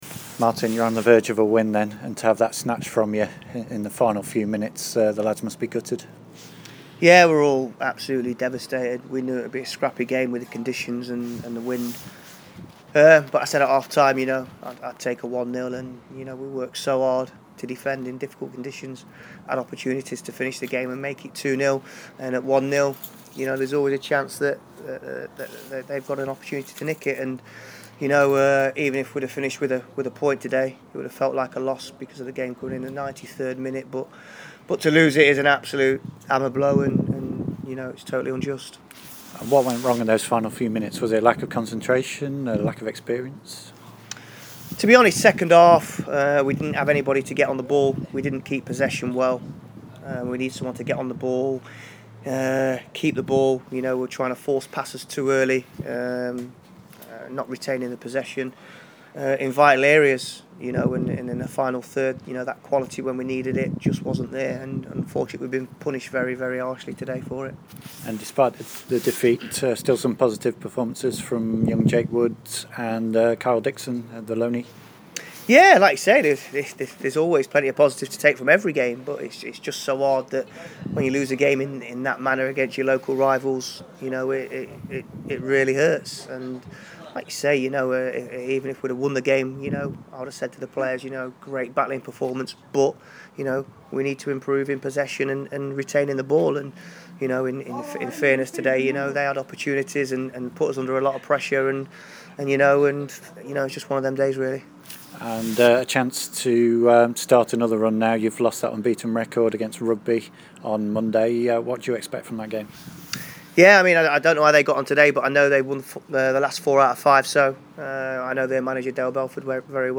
Post-Game Interview